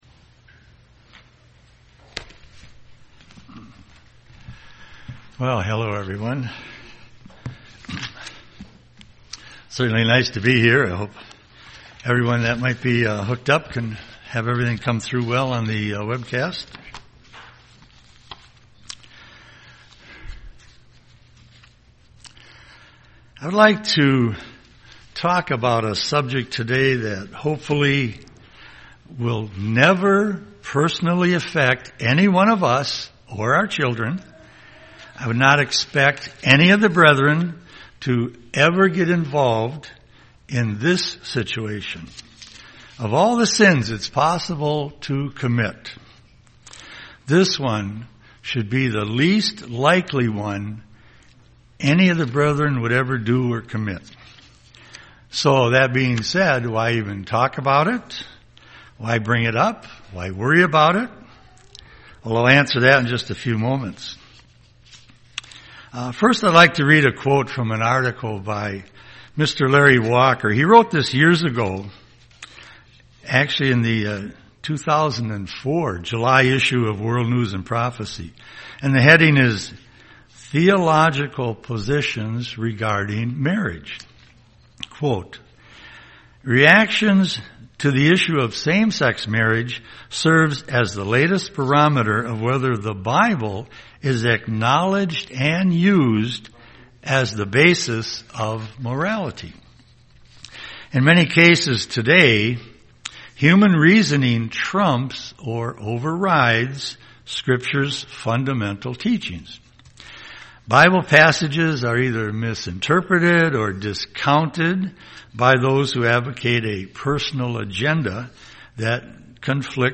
Given in Twin Cities, MN
UCG Sermon polygamy Studying the bible?